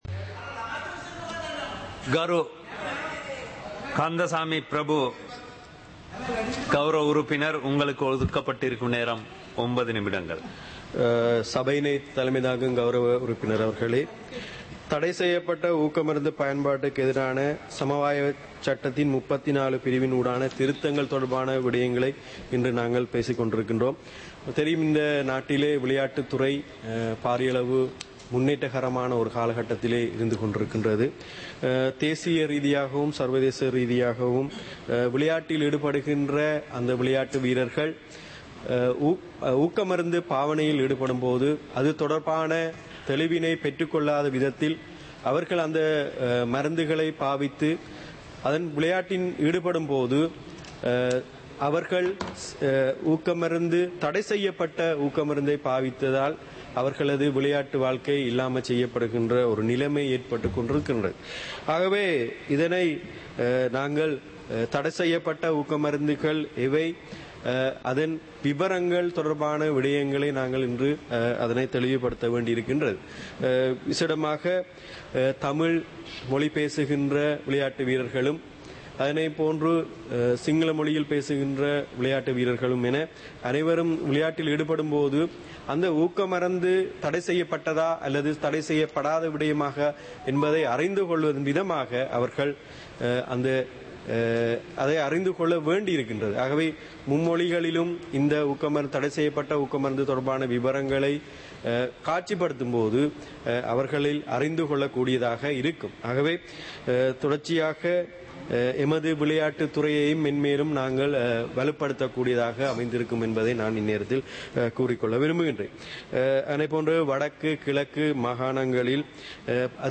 சபை நடவடிக்கைமுறை (2026-04-07)